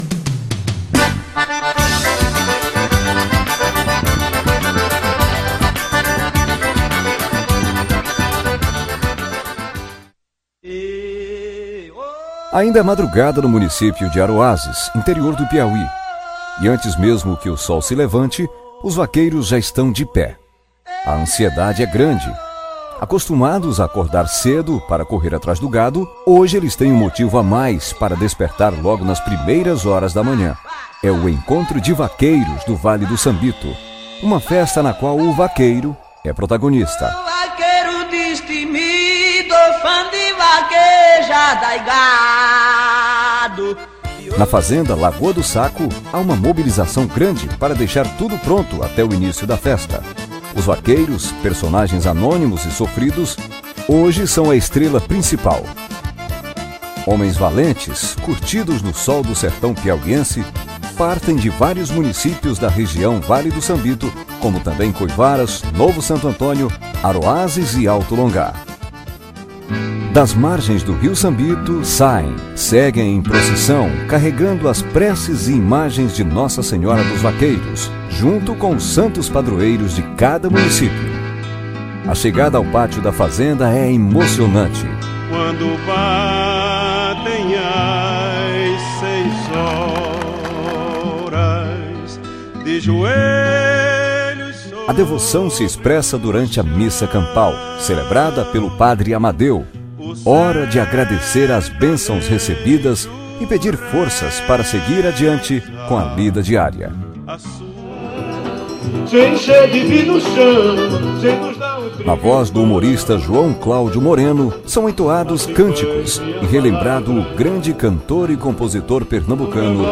vaquejada-aroazes.mp3